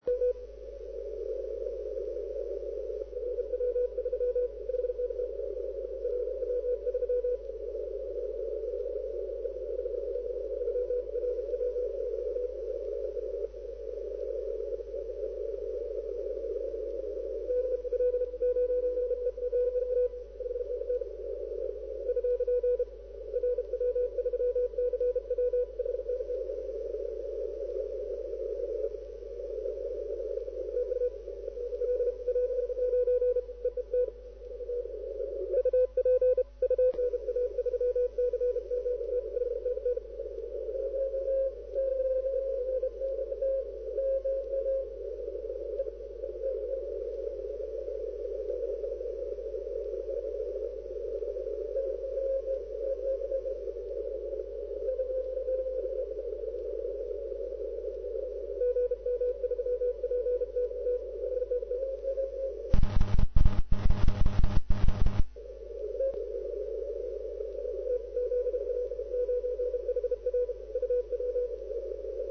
Later 7030 very strong but  could not find the uplink and big E-EU wall
Heel sterk op 40 en 20m  maar overal de bekende EU chaos en opzettelijke storingen.
Maar vanavond 2115 Ned. tijd nog een goed signaal op 14035  fast CW high QSO rate!